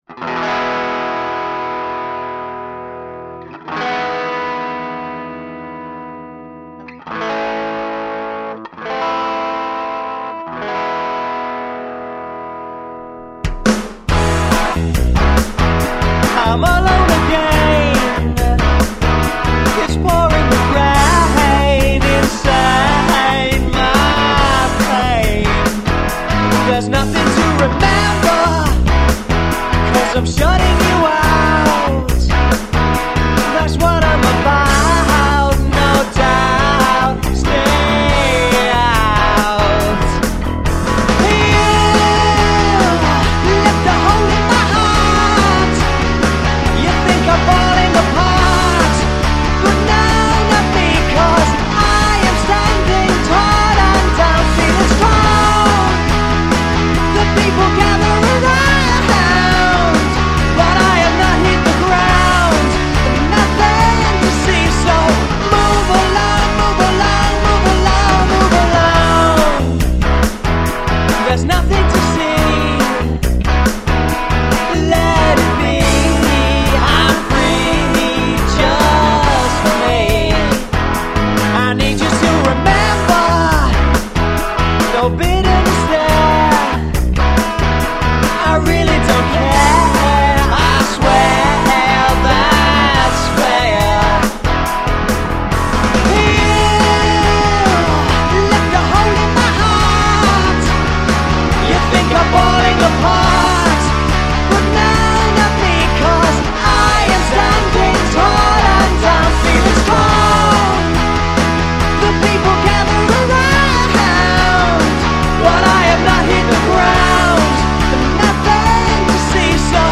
drum and guitar tracks